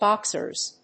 /ˈbɑksɝz(米国英語), ˈbɑ:ksɜ:z(英国英語)/